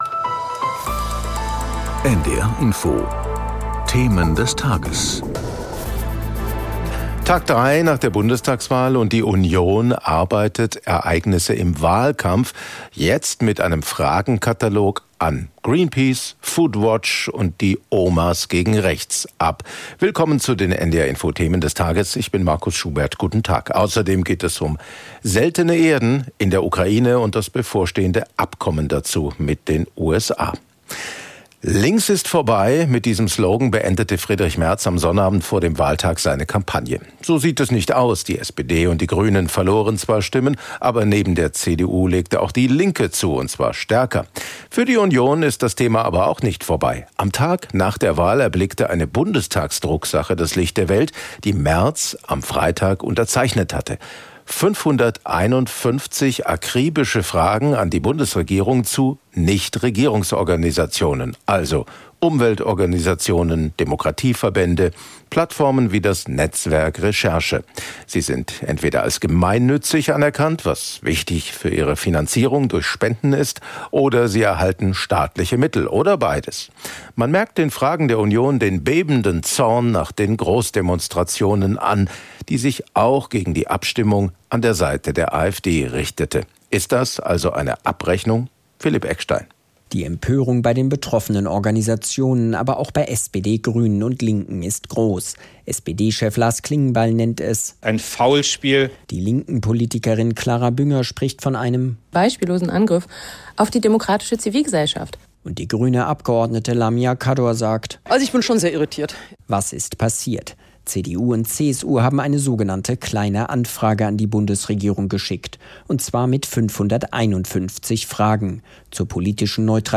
Das Topthema wird aus verschiedenen Perspektiven beleuchtet. In Gesprächen mit Korrespondenten und Interviews mit Experten oder Politikern.